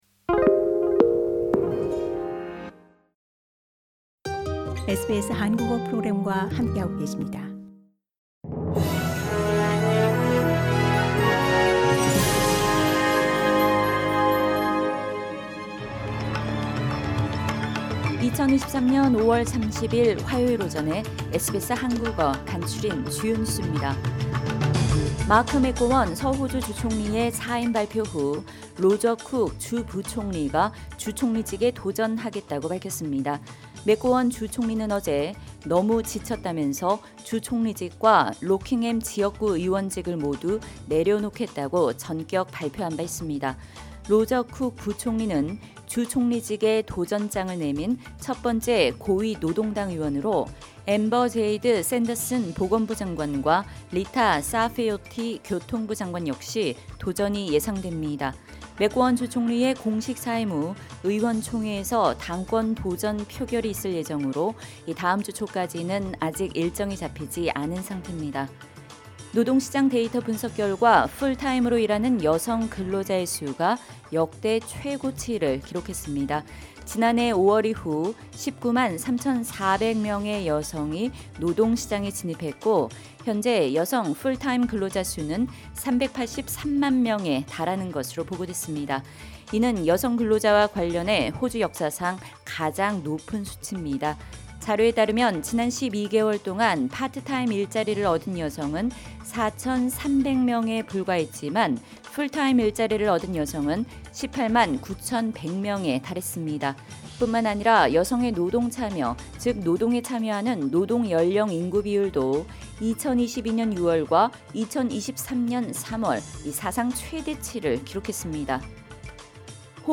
SBS 한국어 아침 뉴스: 2023년 5월 30일 화요일